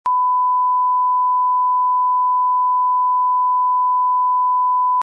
Download Bleep sound effect for free.
Bleep